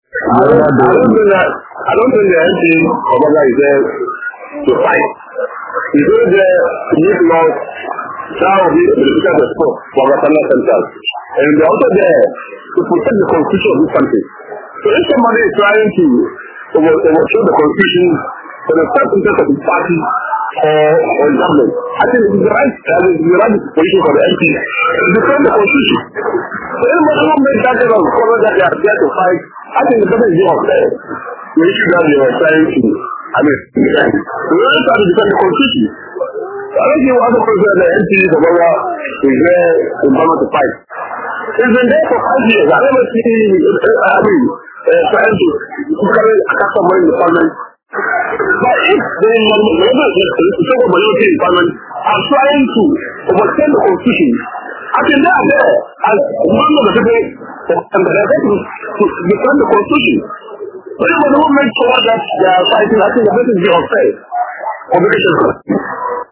Below are some of the voices from residents: